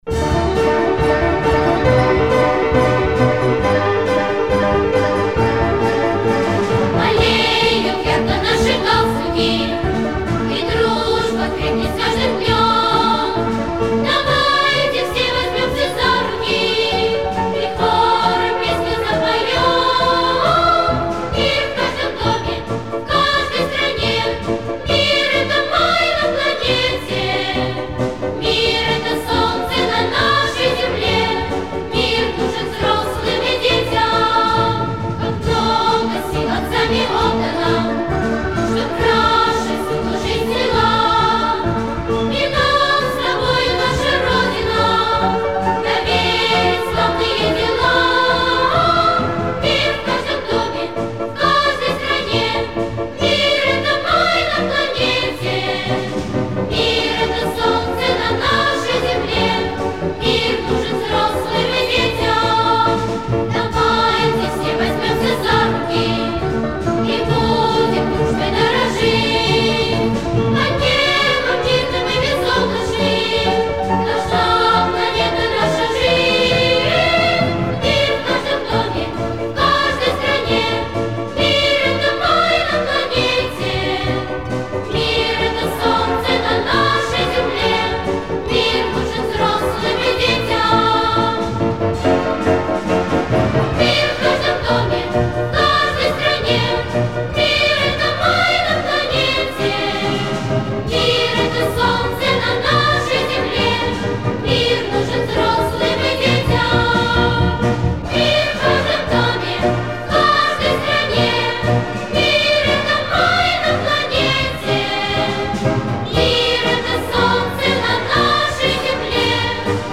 Пионерские песни